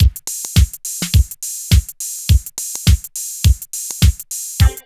136 DRM LP-R.wav